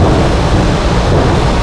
AmbDroneU.wav